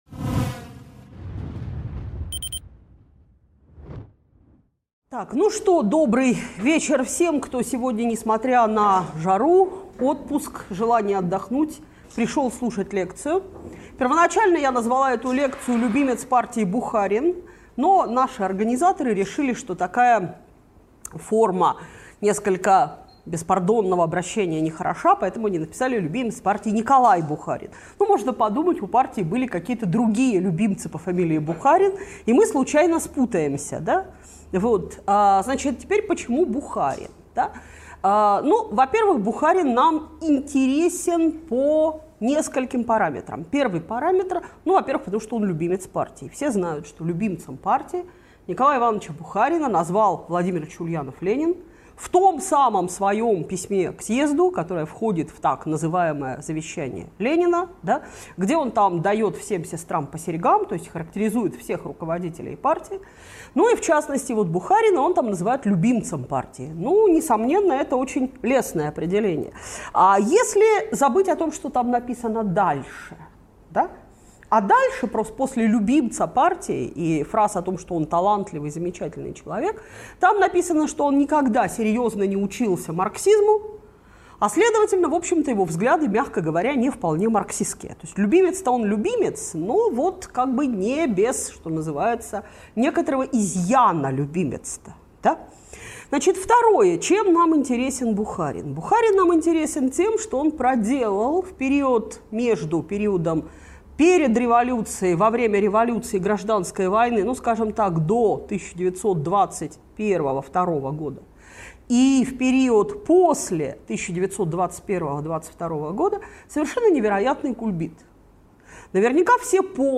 Лекция о жизни и деятельности революционера Николая Бухарина.